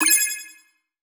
UI_OpenBox.wav